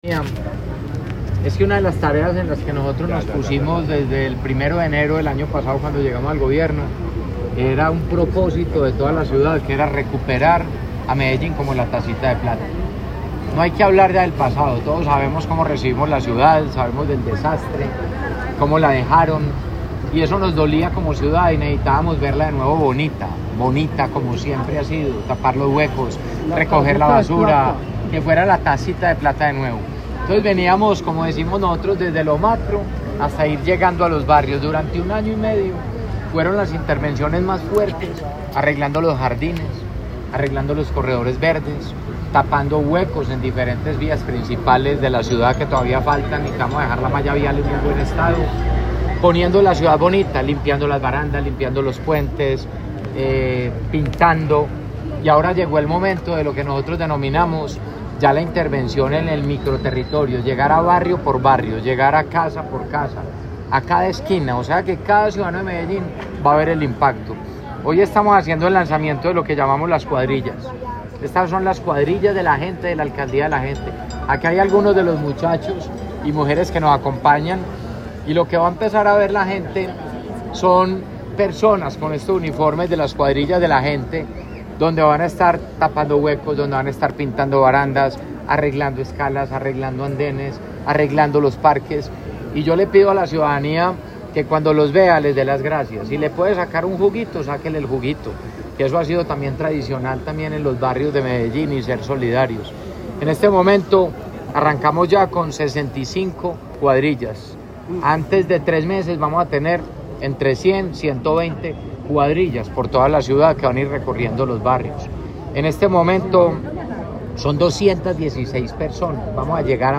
Declaraciones alcalde de Medellín Federico Gutiérrez Como respuesta a un plan de contingencia, que recoge las peticiones de la comunidad, la Administración Distrital identificó 1.600 puntos de intervenciones menores en infraestructura física.
Declaraciones-alcalde-de-Medellin-Federico-Gutierrez-2.mp3